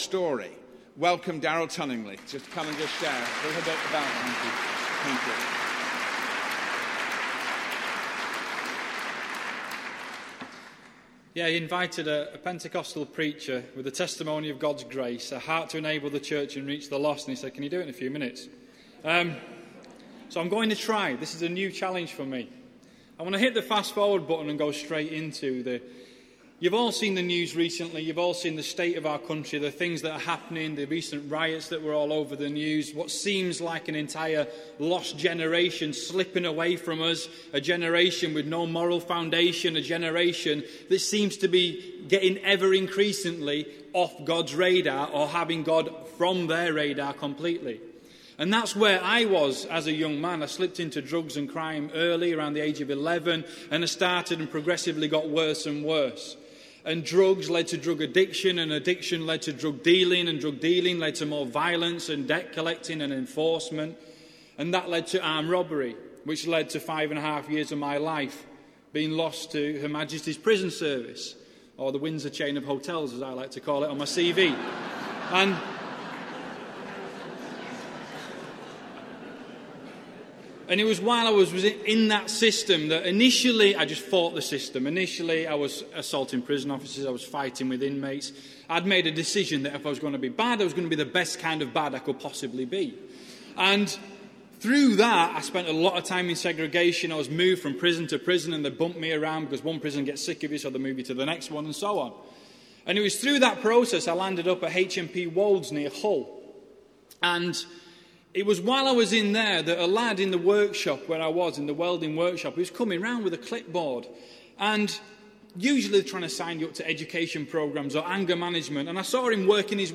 Hierdie is opgeneem tydens ’n konferensie by Blackpool in Engeland in 2012, gereël deur Ellel Ministries.